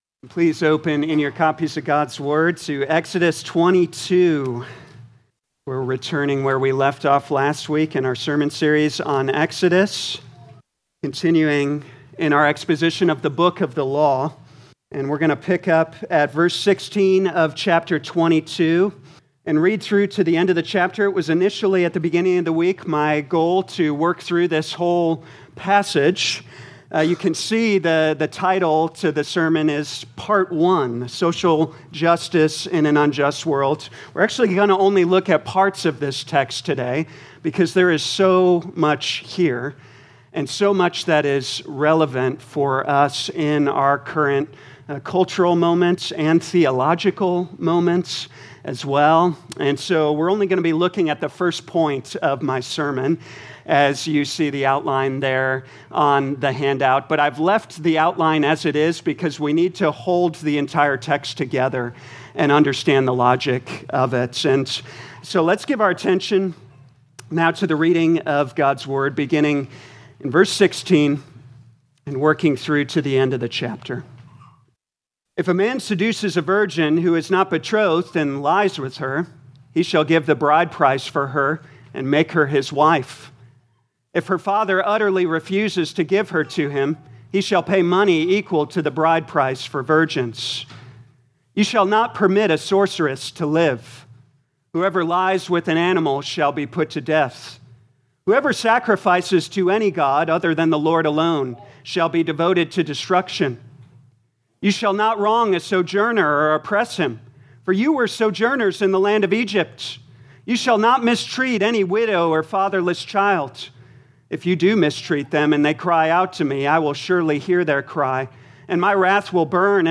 2025 Exodus Morning Service Download